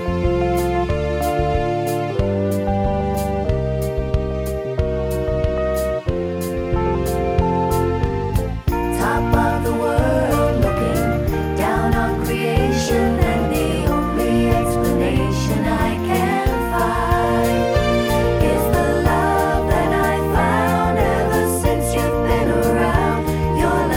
Minus Rhodes Piano Pop (1970s) 2:59 Buy £1.50